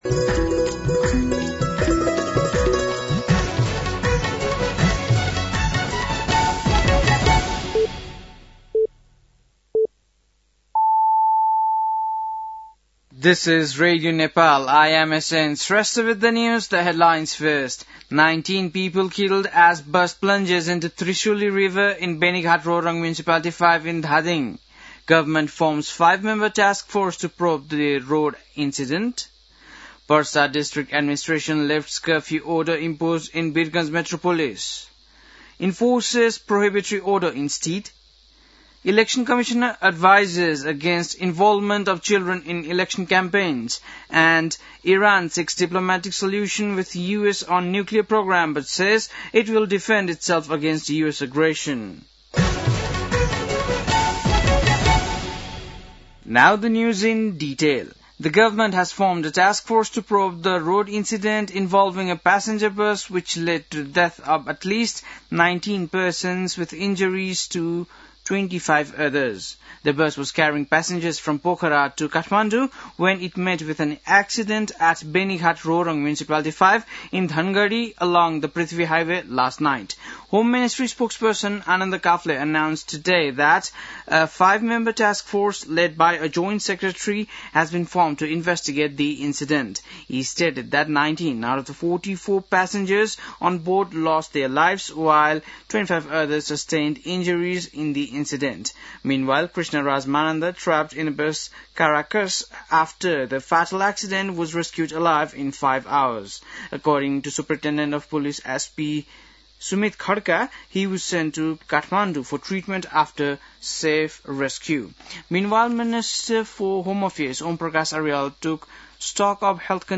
बेलुकी ८ बजेको अङ्ग्रेजी समाचार : ११ फागुन , २०८२
8-PM-English-NEWS-11-11.mp3